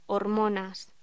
Locución: Hormonas